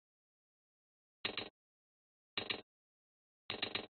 hi hat 12
描述：hi hat
Tag: 镲片 hi_hat Rides